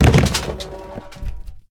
anonHeadbutt.ogg